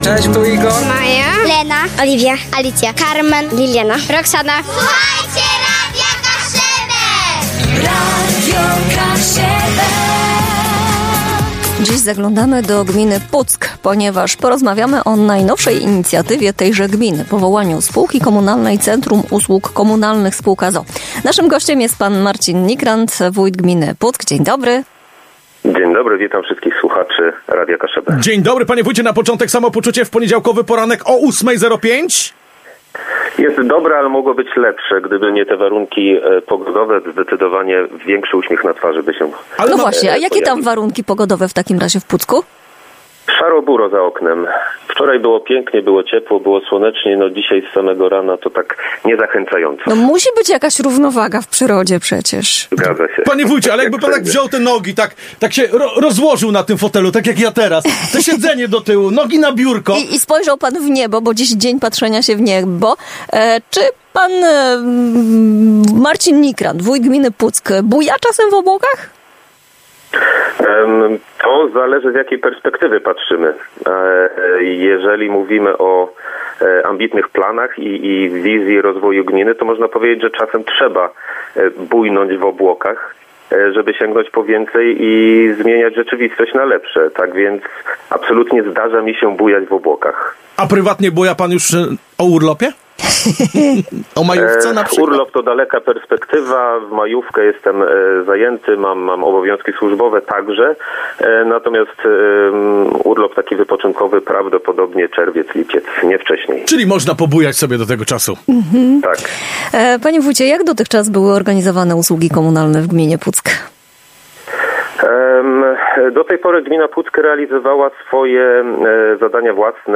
O szczegółach tej ważnej inicjatywy rozmawialiśmy z wójtem gminy Puck, panem Marcinem Nikrant.
rozmowa_M.Nikrant.mp3